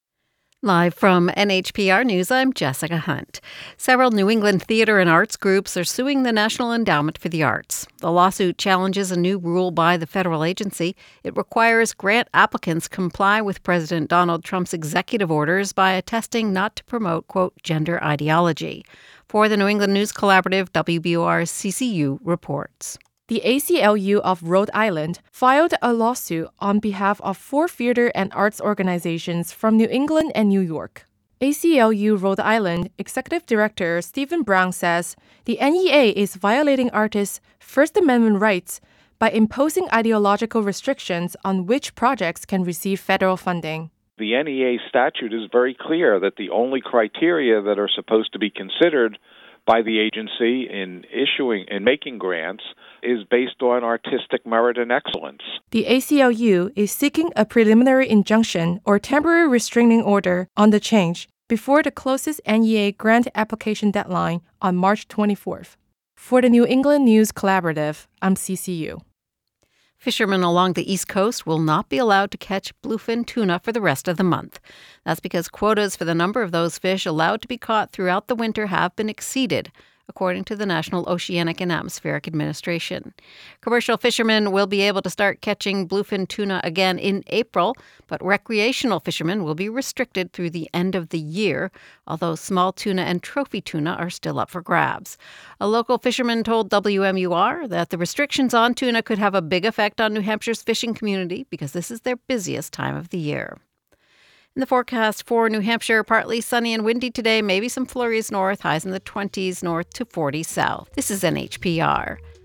NHPR Newscasts - NHPR morning newscast for Saturday, March 8, 2025